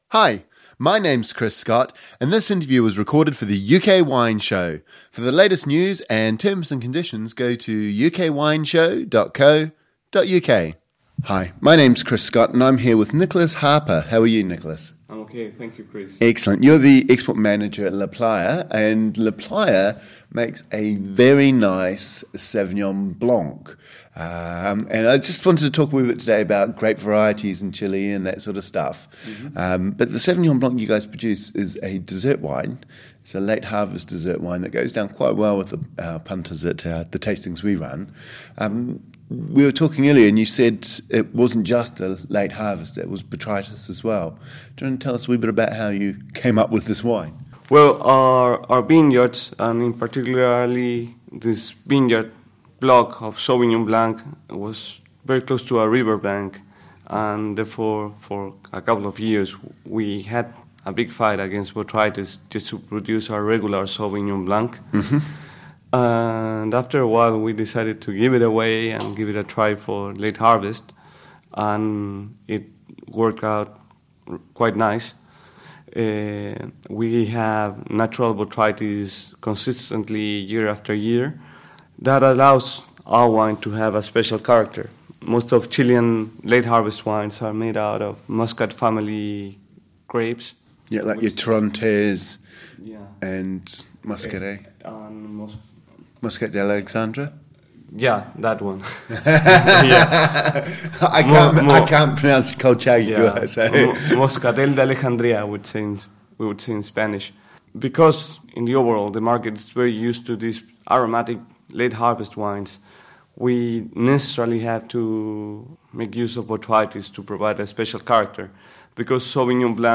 In this second interview we hear about La Playa's Late Harvest Sauvignon Blanc and how the winery began producing it by letting botrytis take hold in vineyards that were too humid to produce a regular dry style. We find out how botrytis creates deeper, sweeter, aromatic characters balanced by the fresh fruit acidity of Sauvignon Blanc.